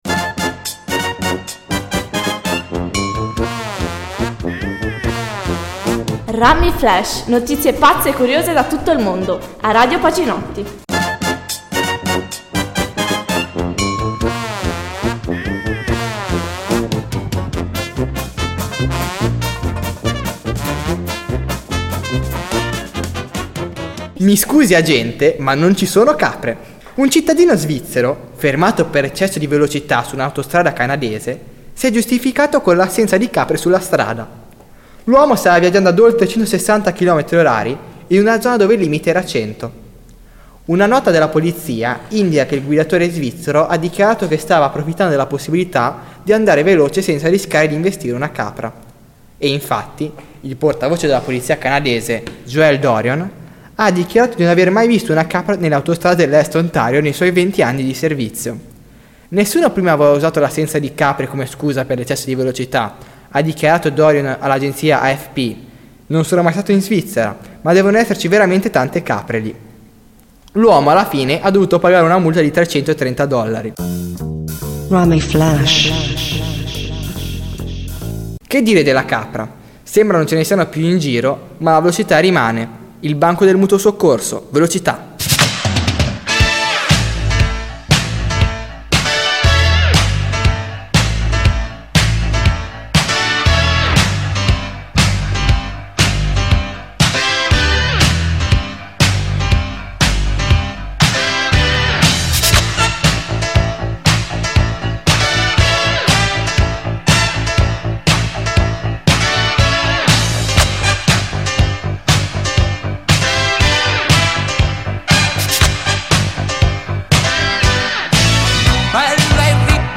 Radioweb Pacinotti Notizie curiose e brano di musica con relazione alla notizia